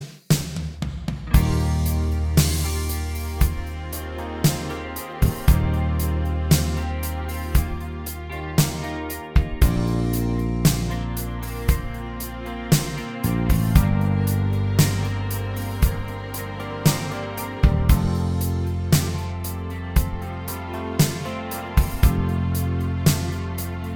Minus Solo Guitar Pop (1980s) 4:29 Buy £1.50